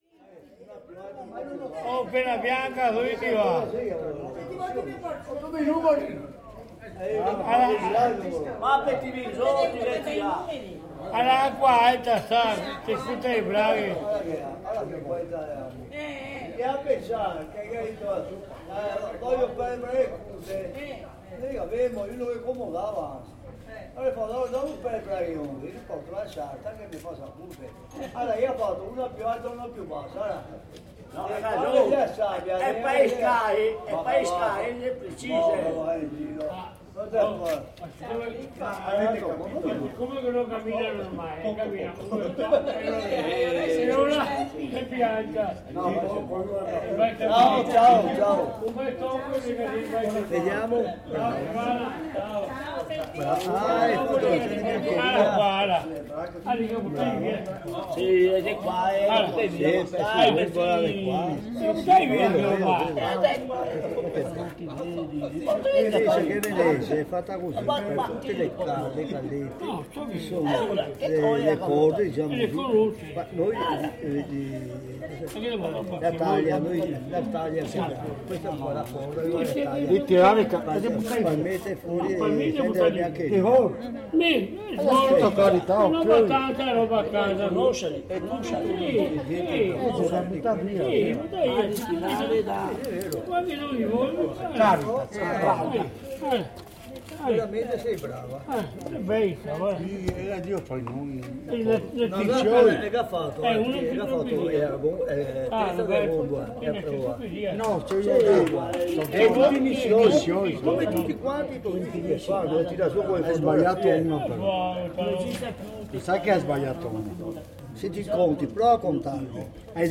Des cercles d’amis se forment et se dissolvent joyeusement, transformant l’allée Garibaldi en ruche musicale.